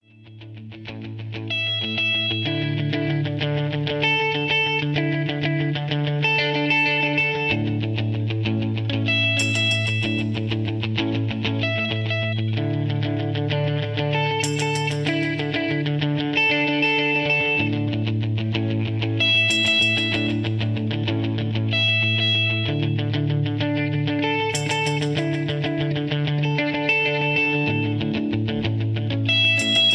karaoke, rock and roll